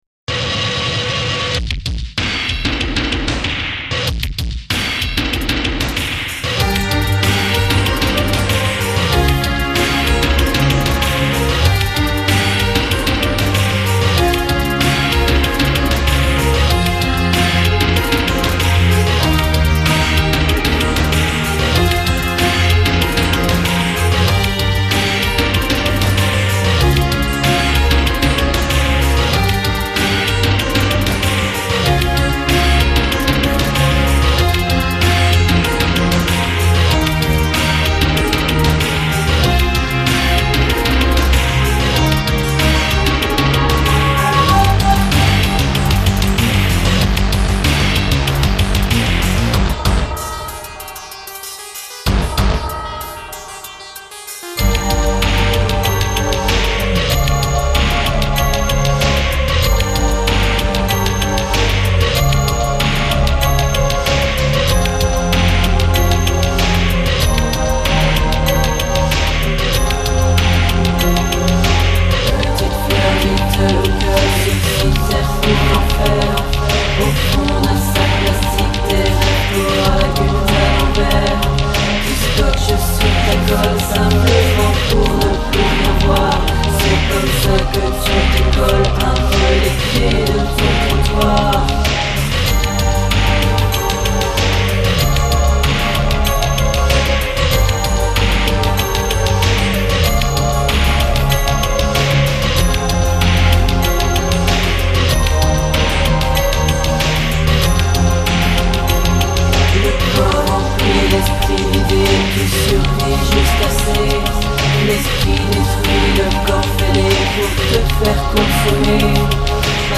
Rock mélodique: